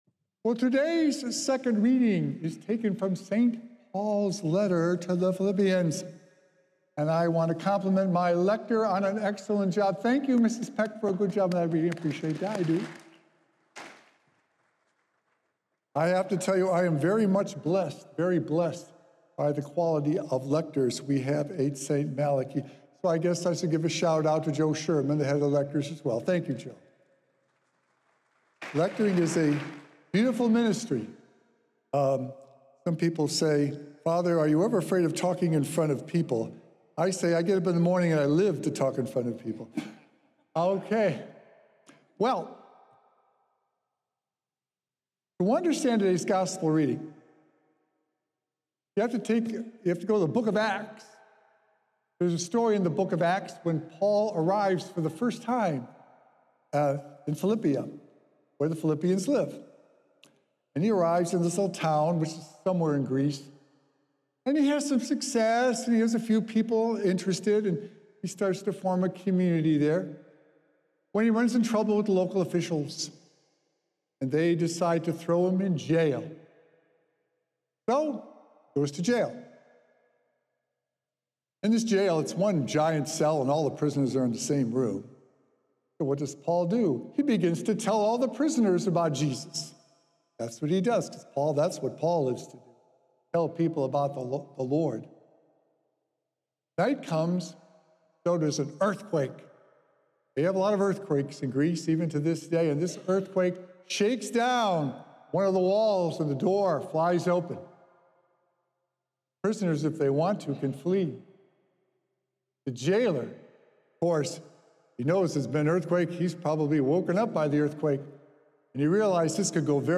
Thanksgiving is a beautiful way to bring others to Christ. Recorded Live on Sunday, April 6th, 2025 at St. Malachy Catholic Church.